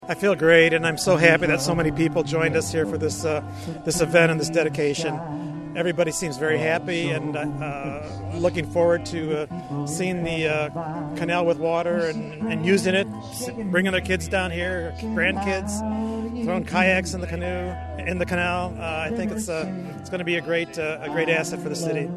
There was a big crowd for a ribbon cutting by the Illinois and Michigan Canal tollhouse for the canal re-watering project.